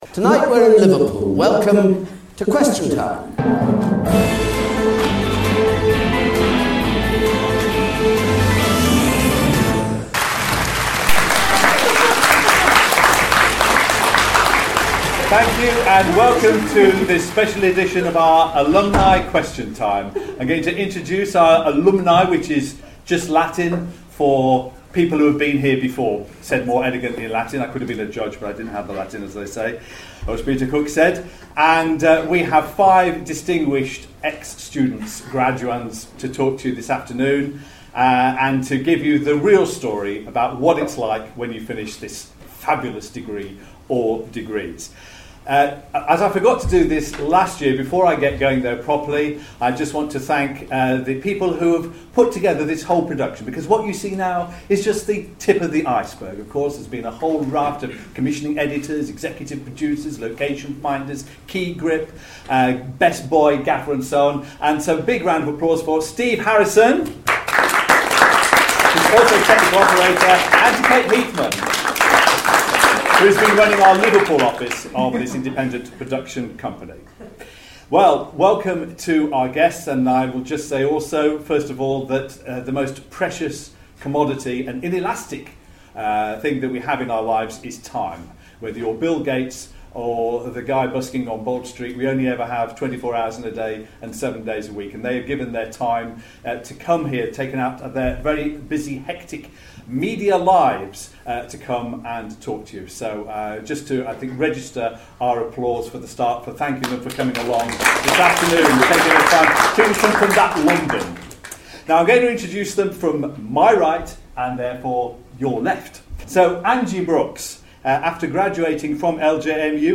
What happened when five former journalism students answered questions at the Journalism Alumni Question Time 2015 at Liverpool John Moores University.